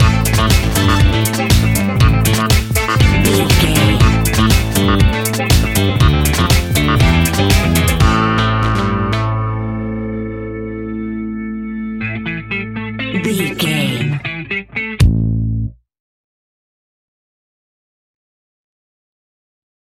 Aeolian/Minor
funky
groovy
uplifting
driving
energetic
bass guitar
electric guitar
drums
synthesiser
electric organ
brass
funky house
disco house
electro funk
upbeat
synth leads
Synth Pads
synth bass
drum machines